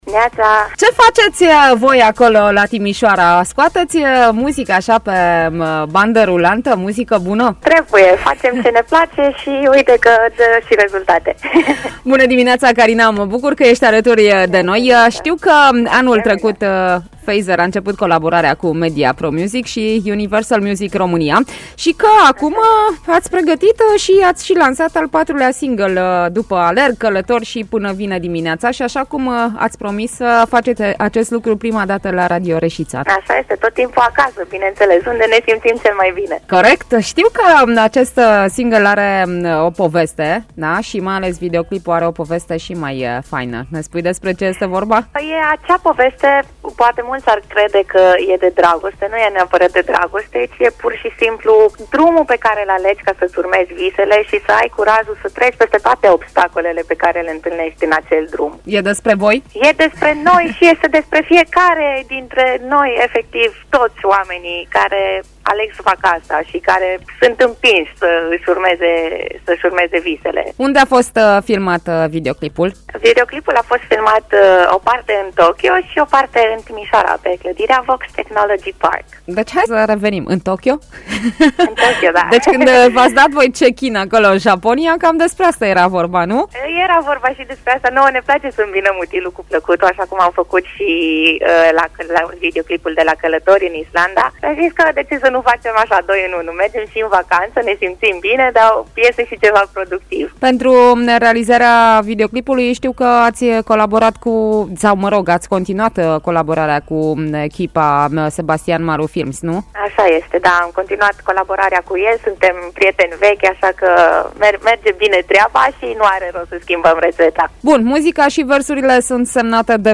voce
chitară
clape